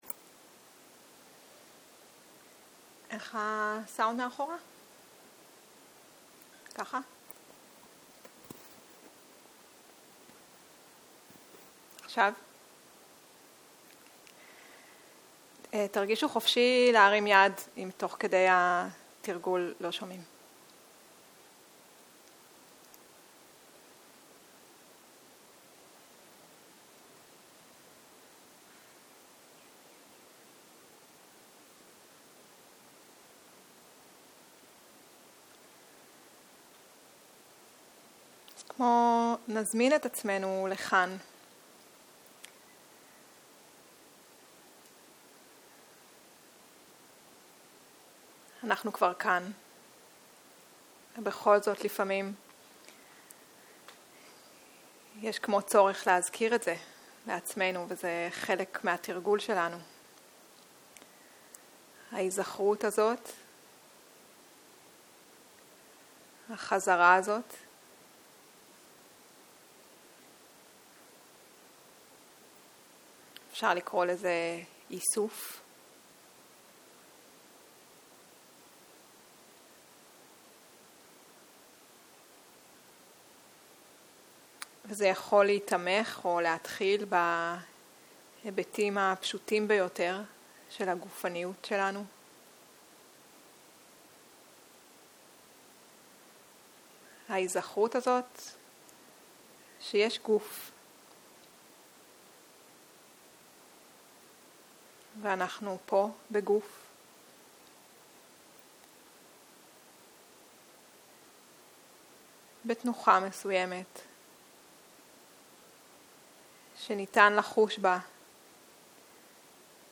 צהריים - מדיטציה מונחית - חזרה לגוף - שיחה 3